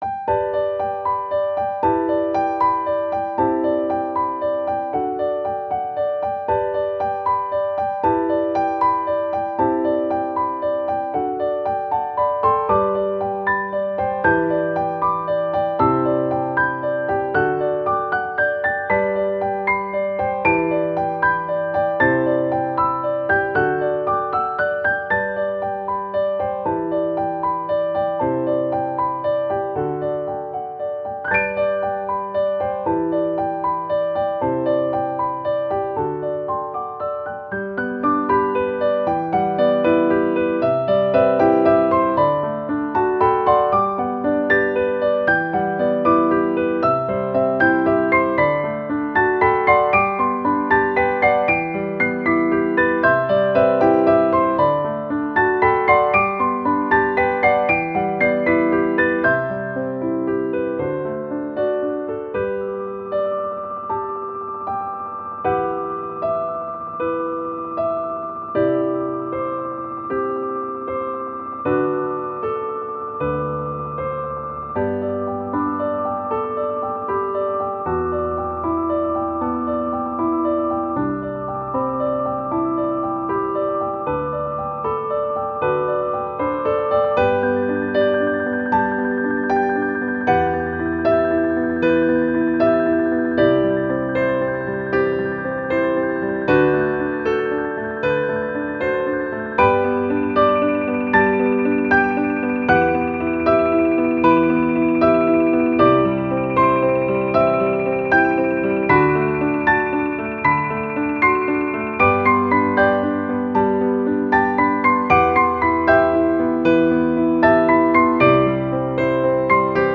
• 暗めのしっとりしたピアノ曲のフリー音源を公開しています。
ogg(L) - しっとり 瑞々しい 流麗
雪解け水のように、澄みきった流れのピアノメロディ。